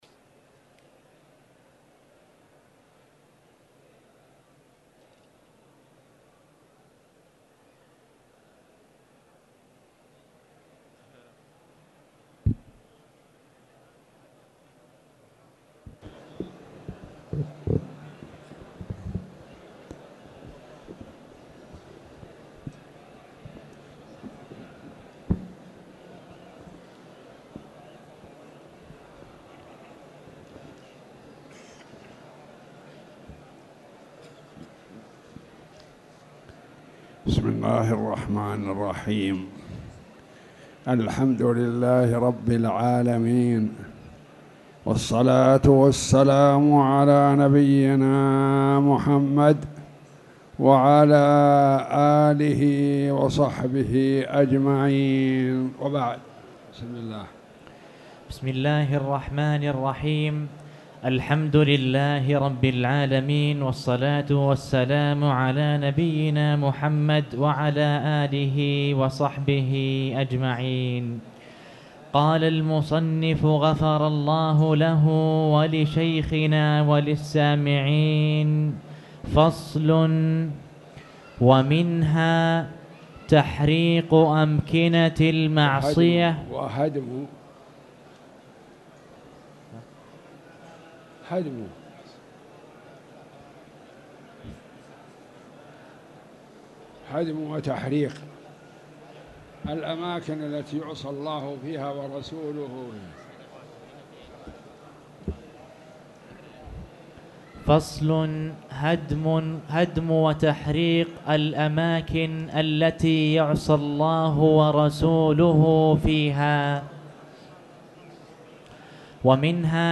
تاريخ النشر ٢٤ ربيع الأول ١٤٣٨ هـ المكان: المسجد الحرام الشيخ